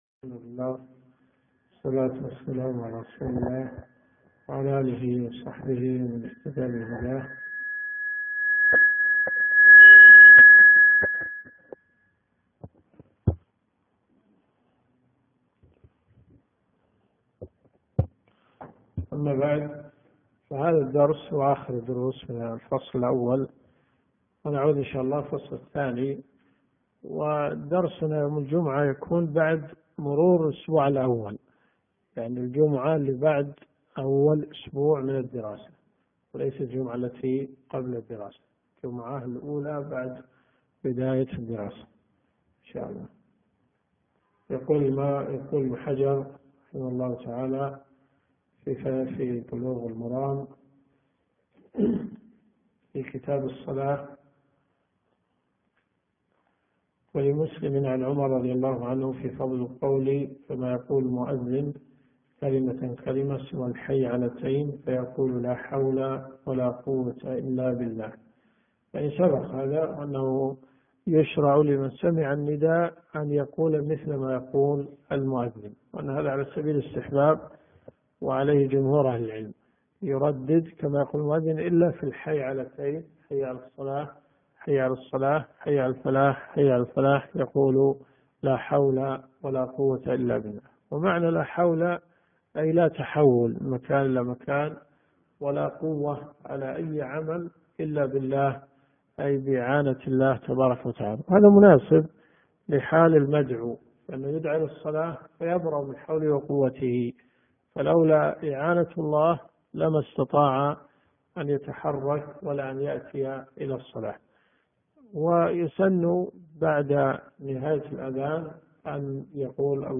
دروس صوتيه ومرئية تقام في جامع الحمدان بالرياض - فتاوى .